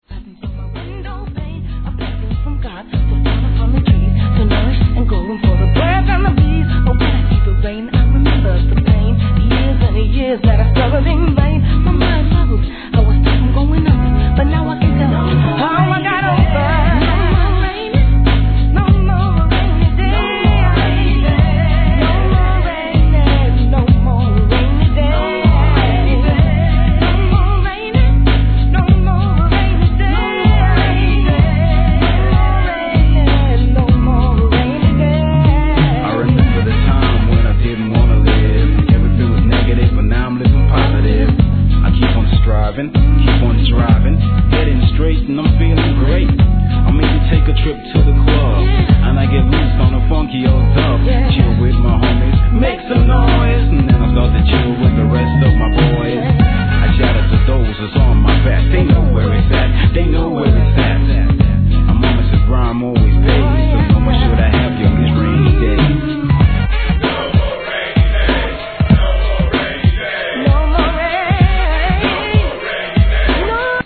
HIP HOP/R&B
優しさに包まれた洒落オツUK SOUL♪